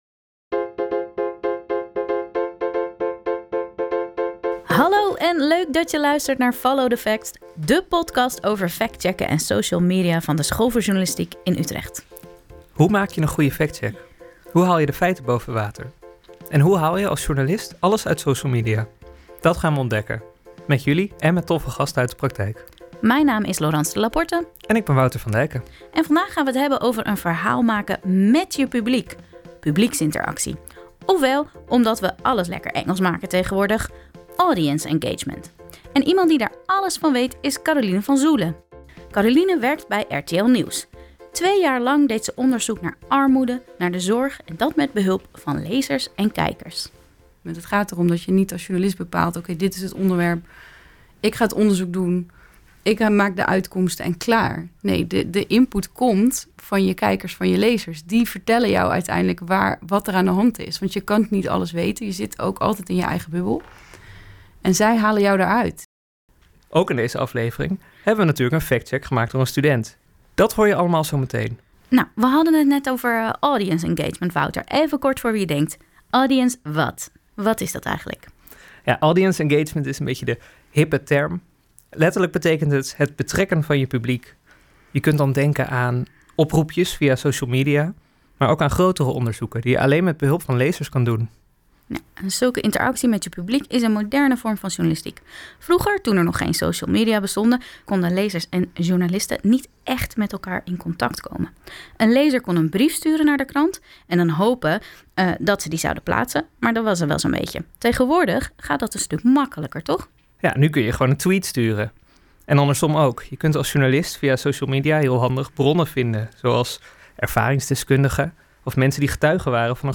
Jingles intro en outro
Fragment Tenniswedstrijd